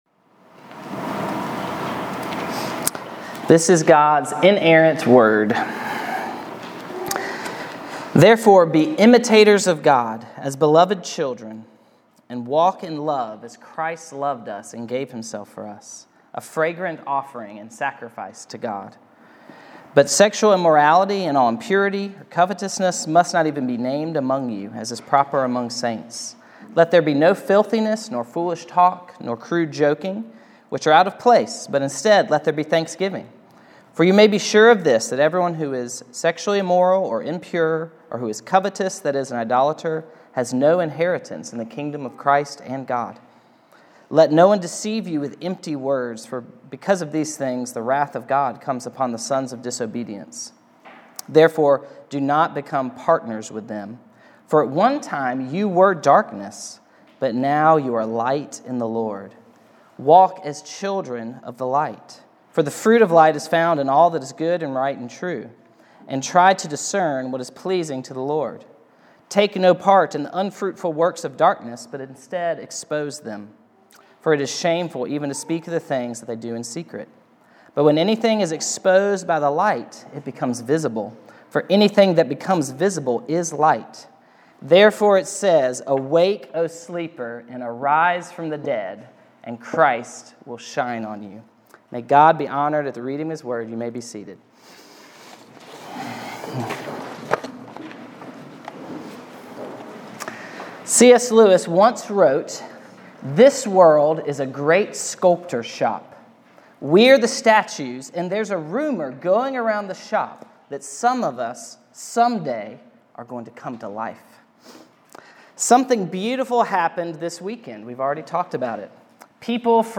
Passage: Ephesians 5:1-14 Preacher